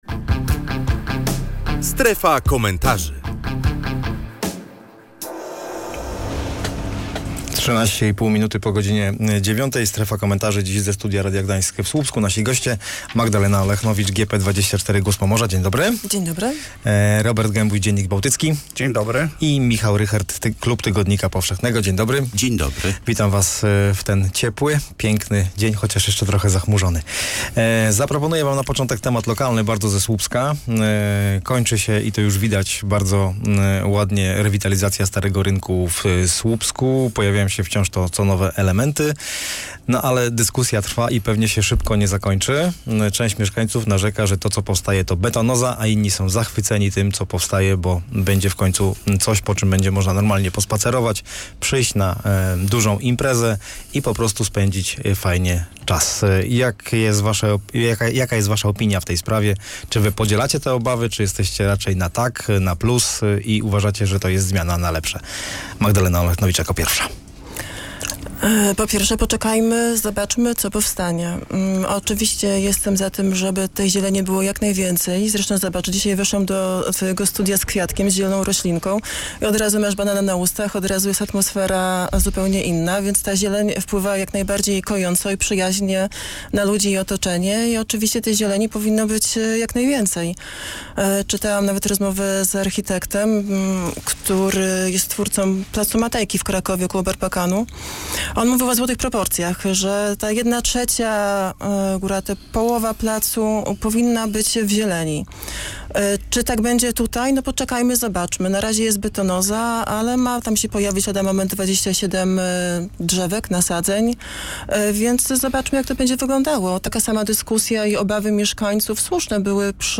Między innymi na te pytania odpowiadali goście Strefy Komentarzy.